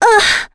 Laias-Vox_Damage_02.wav